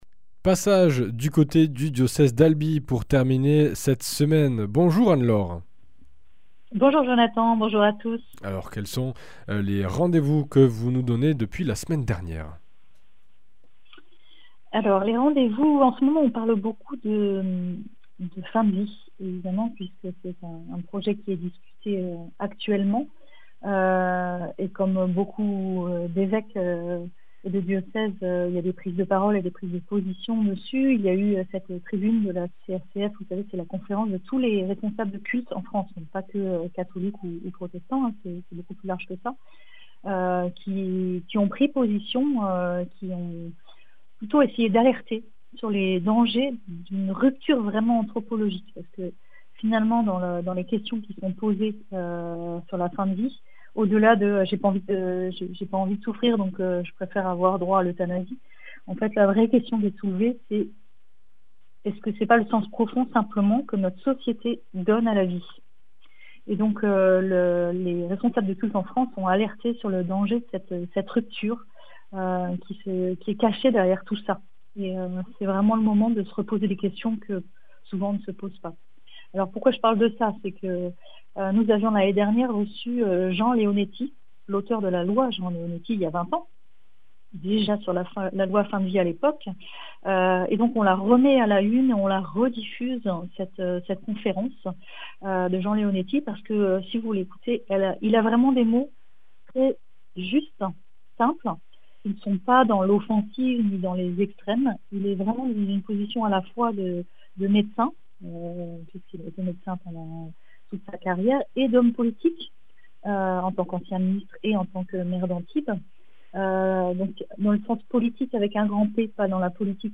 Voici la rediffusion de la conférence débat sur la 𝐅𝐢𝐧 𝐝𝐞 𝐯𝐢𝐞 𝐚𝐯𝐞𝐜 𝐉𝐞𝐚𝐧 𝐋𝐞𝐨𝐧𝐞𝐭𝐭𝐢 Mardi 28 Mai 20h en duplex entre Antibes, Albi et Castres.